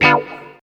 137 GTR 1 -L.wav